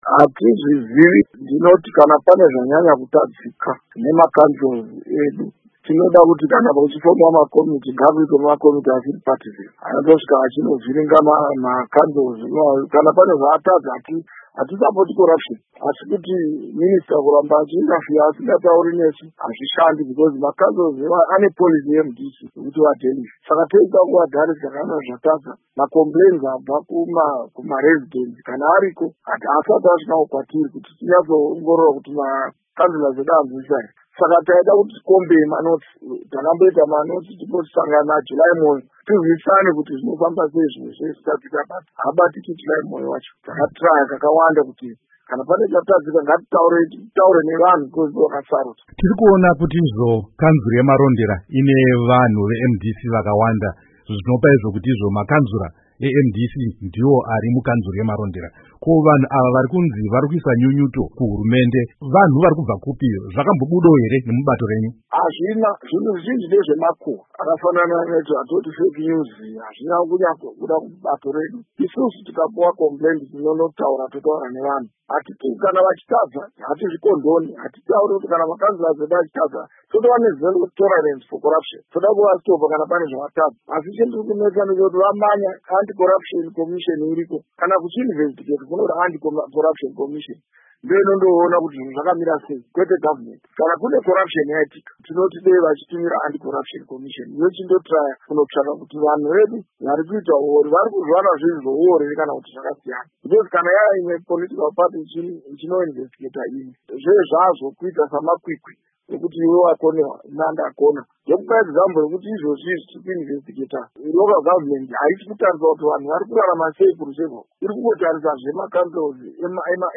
Hurukuro naVaElias Mudzuri